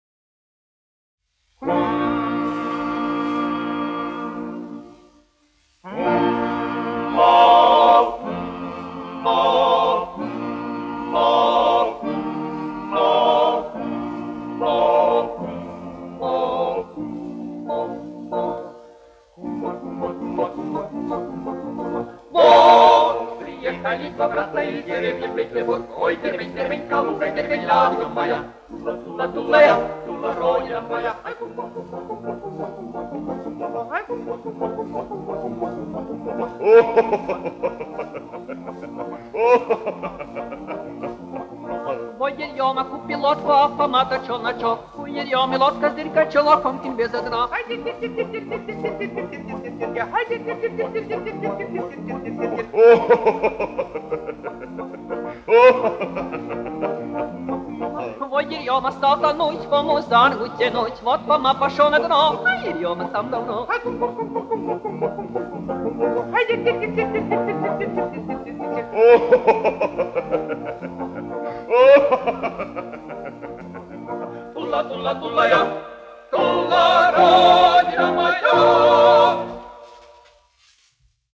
Harmonika : krievu tautas dziesma
Latvijas Filharmonijas vīru vokālais kvartets, izpildītājs
1 skpl. : analogs, 78 apgr/min, mono ; 25 cm
Skaņuplate